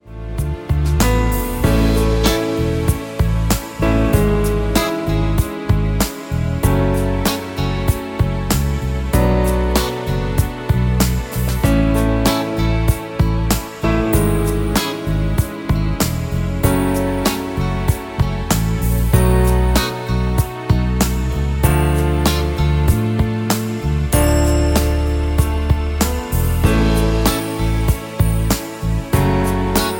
Dm
MPEG 1 Layer 3 (Stereo)
Backing track Karaoke
Pop, 1990s